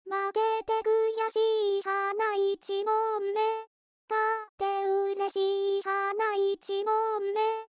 これは、「ド」と「レ」の二つの音だけから成っています。
上の例でも、4小節目と8小節目の最後は、上の「レ」の音で終止しています。
歌声は、いずれも「初音ミク」を使用。）